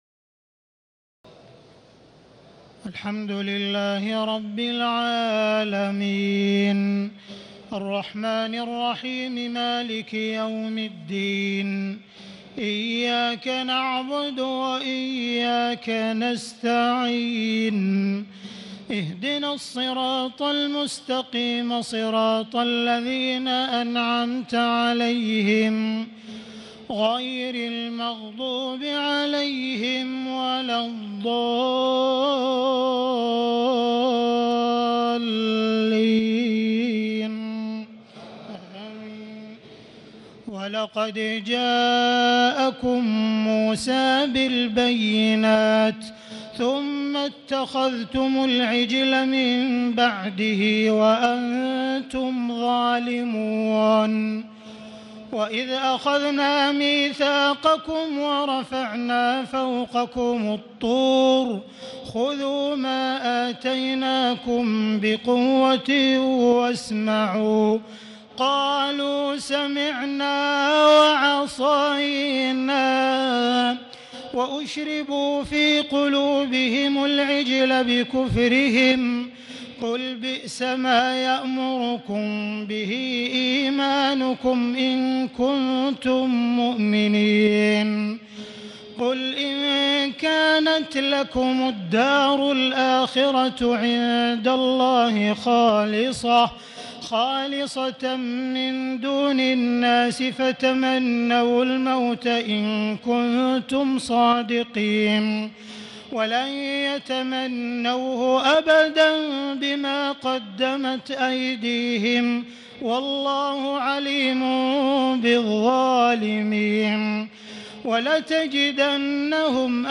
تهجد ليلة 21 رمضان 1439هـ من سورة البقرة (92-141) Tahajjud 21 st night Ramadan 1439H from Surah Al-Baqara > تراويح الحرم المكي عام 1439 🕋 > التراويح - تلاوات الحرمين